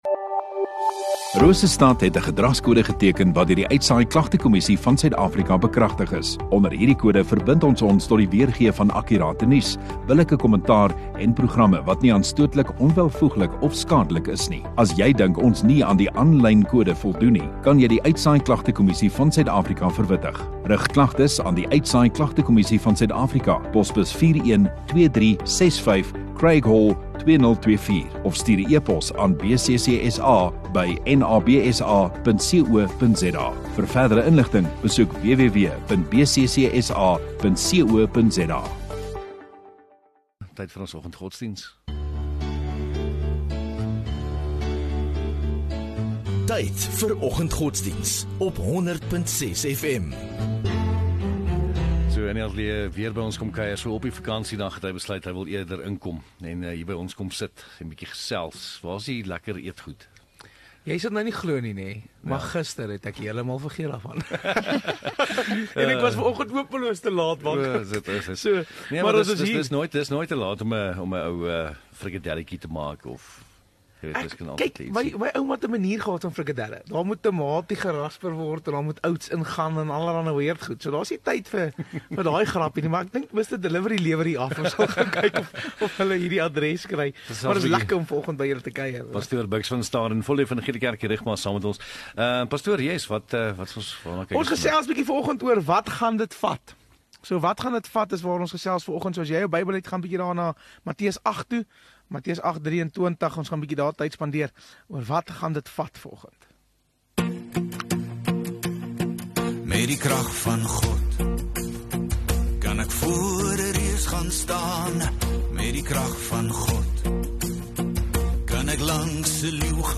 21 Mar Donderdag Oggenddiens